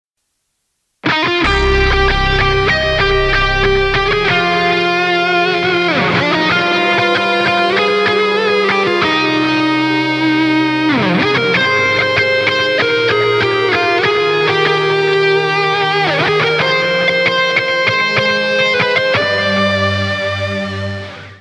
• Качество: 320, Stereo
без слов
русский рок
кавер
соло на электрогитаре
гитарное соло